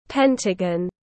Hình ngũ giác tiếng anh gọi là pentagon, phiên âm tiếng anh đọc là /’pentəgən/.
Pentagon /’pentəgən/